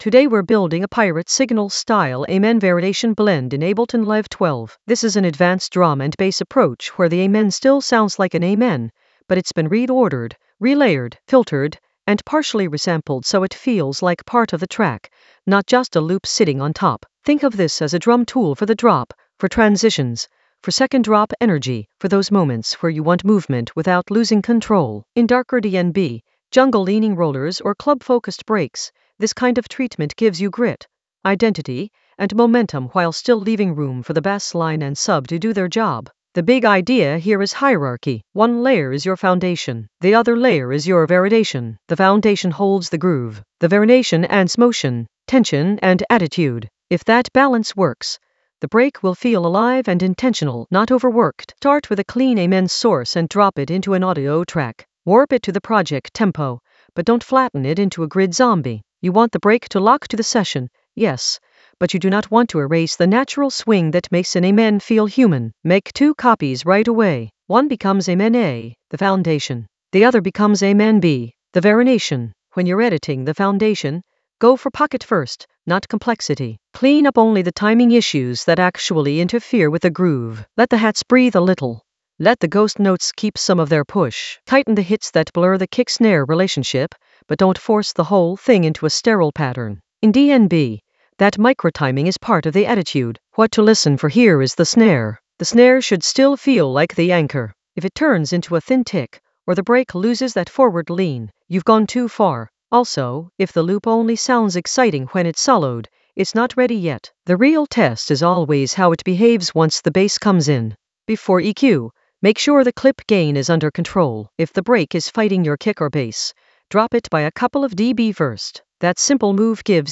An AI-generated advanced Ableton lesson focused on Pirate Signal approach: an amen variation blend in Ableton Live 12 in the Mixing area of drum and bass production.
Narrated lesson audio
The voice track includes the tutorial plus extra teacher commentary.